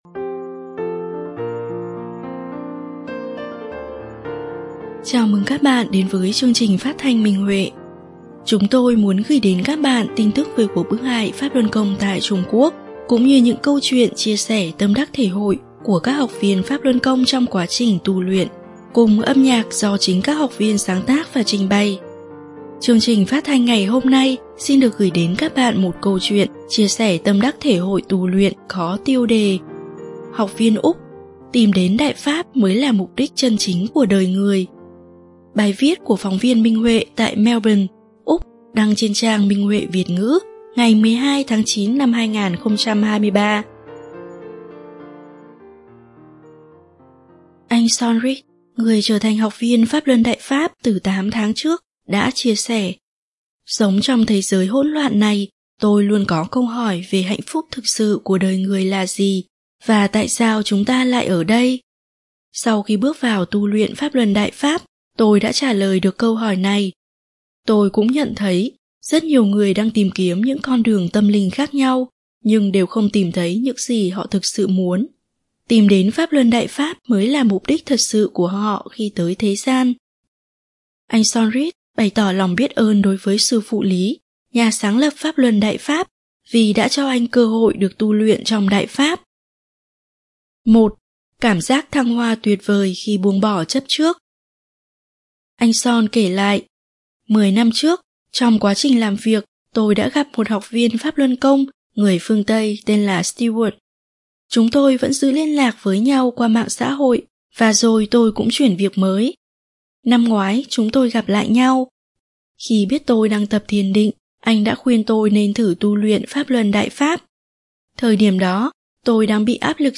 Chương trình phát thanh số 1068: Bài viết chia sẻ tâm đắc thể hội trên Minh Huệ Net có tiêu đề Học viên Úc: Tìm đến Đại Pháp mới là mục đích chân chính của đời người, bài viết của đệ tử Đại Pháp tại Melbourne, Úc.